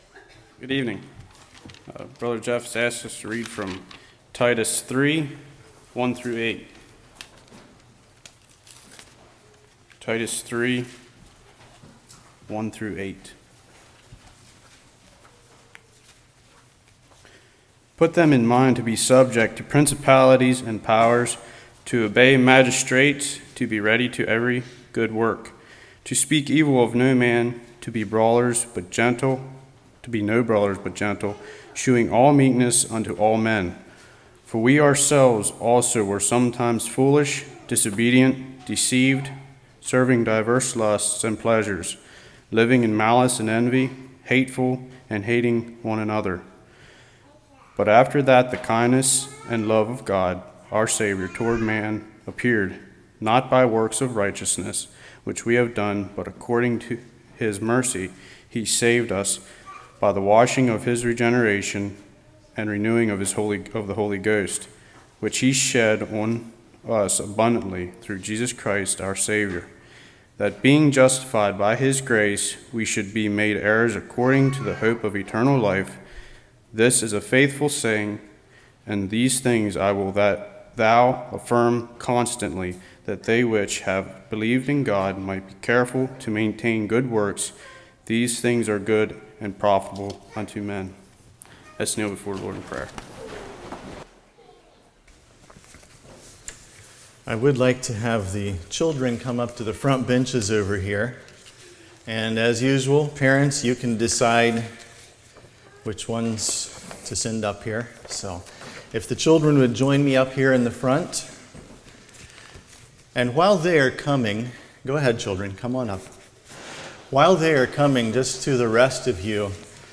Titus 3:1-8 Service Type: Evening Can anyone be saved who does not obey all the New Testament all the time?